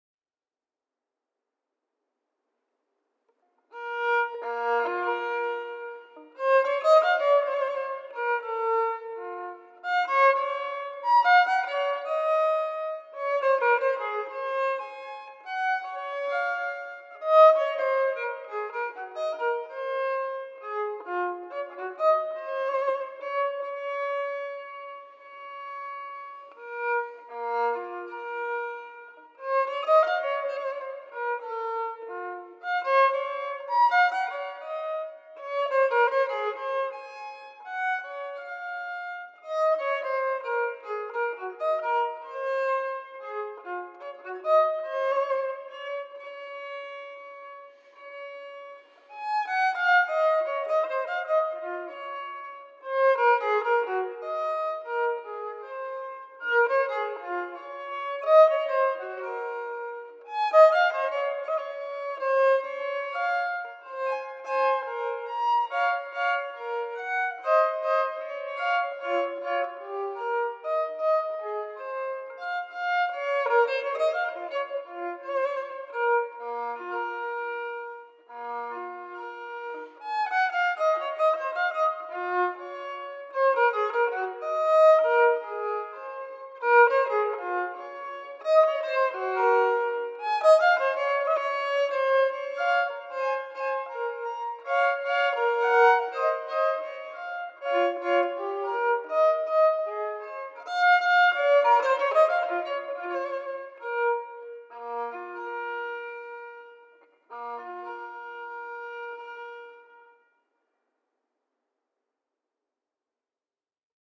Violin
So I will find my way  through the ‘Handskrift’ in a dis-orderly, the way I practise it, and chose this melancholy sarabandish minuet as  starting point.